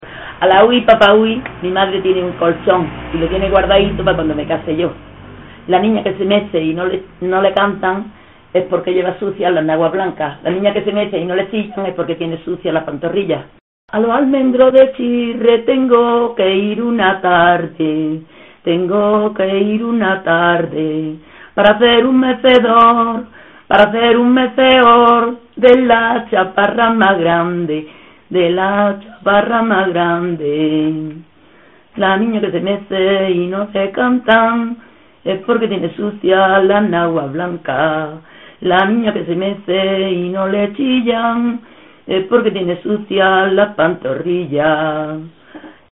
Materia / geográfico / evento: Canciones de mecedor Icono con lupa
Zafarraya (Granada) Icono con lupa
Secciones - Biblioteca de Voces - Cultura oral